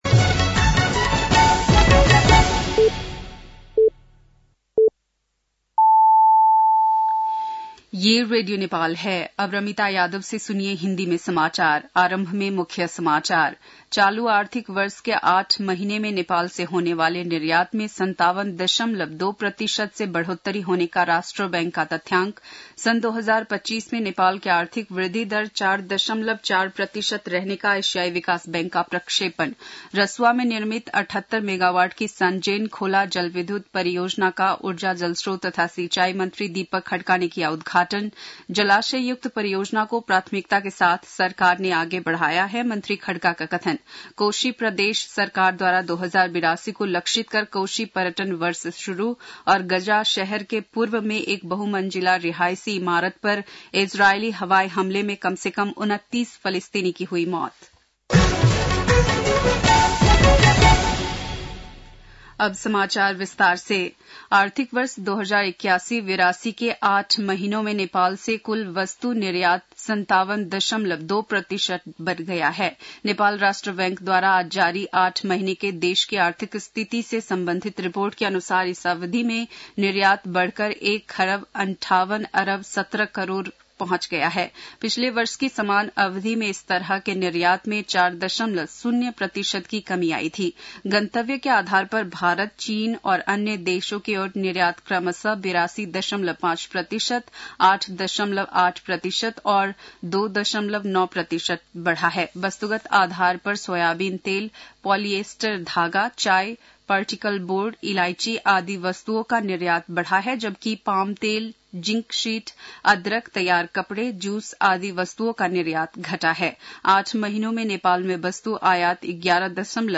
बेलुकी १० बजेको हिन्दी समाचार : २७ चैत , २०८१